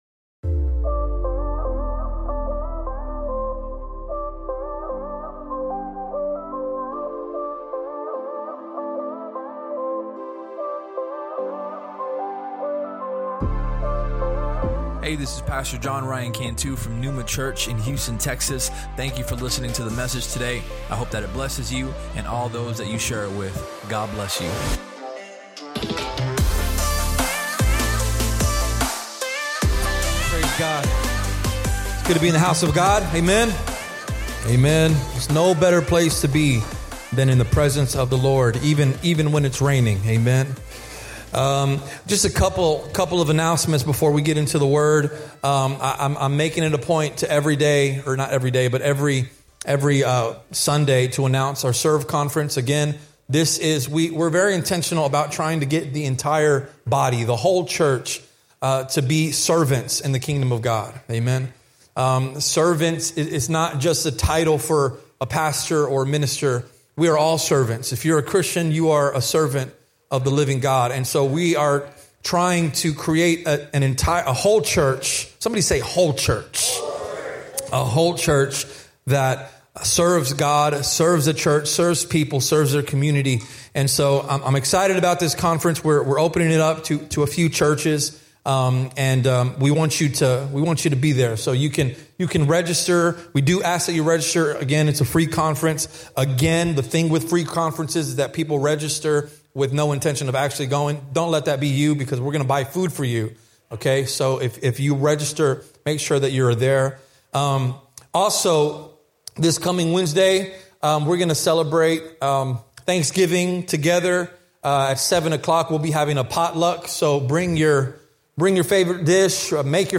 Sermon Topics: Giving, Sacrifice Links: Referenced Sermon: Good & Faithful If you enjoyed the podcast, please subscribe and share it with your friends on social media.